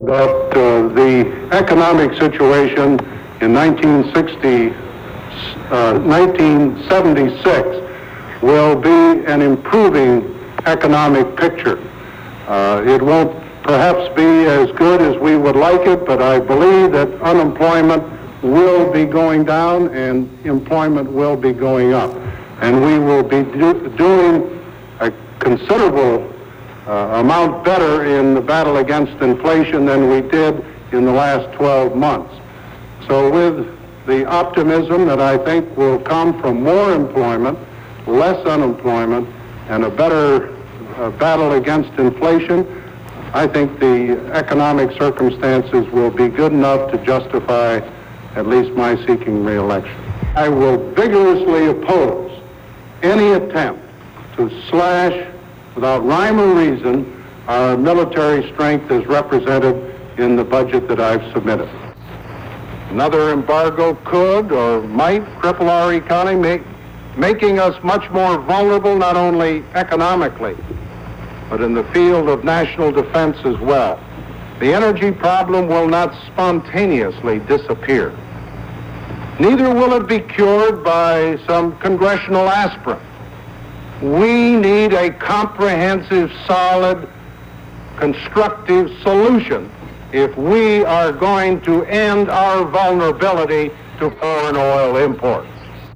Broadcast on CBS-TV, February 2, 1975.